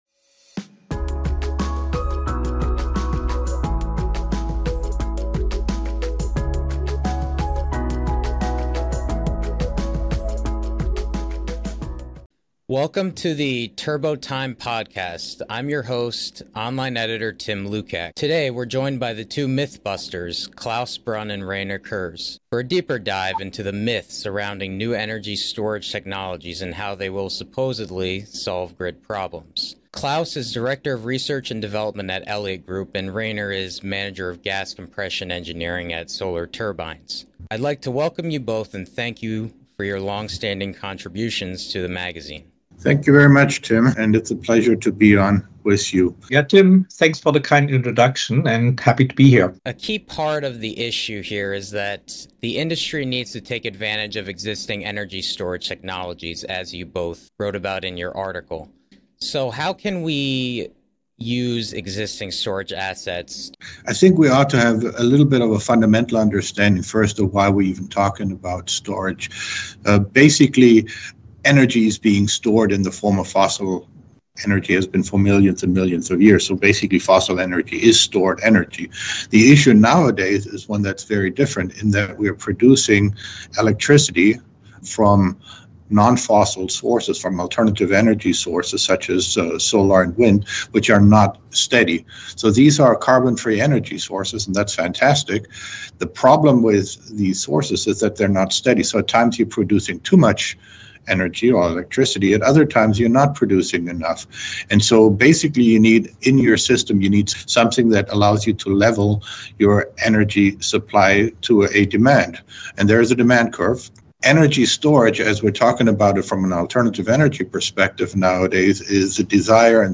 Interviewing